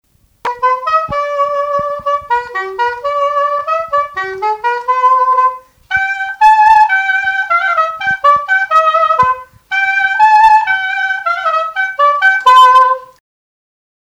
Note figure du quadrille moderne
danse : quadrille : chaîne anglaise
Pièce musicale inédite